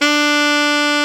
Index of /90_sSampleCDs/Roland LCDP07 Super Sax/SAX_Alto Short/SAX_A.ff 414 Sh
SAX A.FF D08.wav